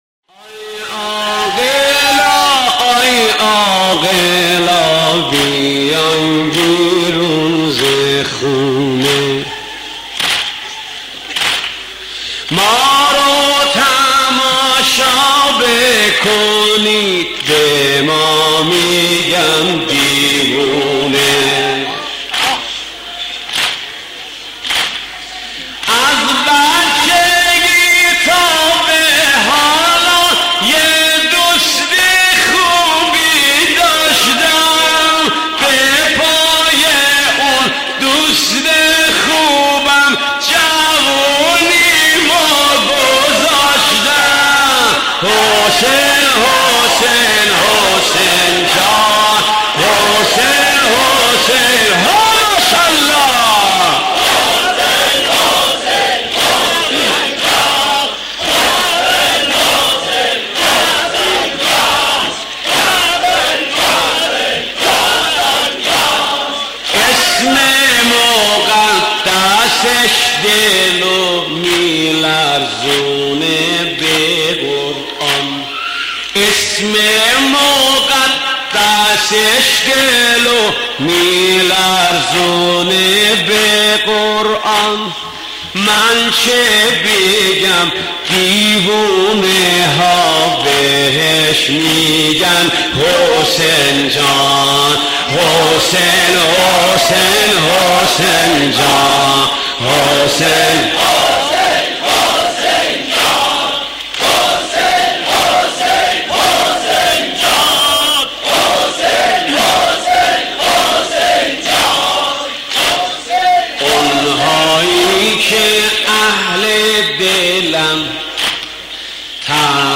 مداحی شنیدنی استاد محمد علی کریمخانی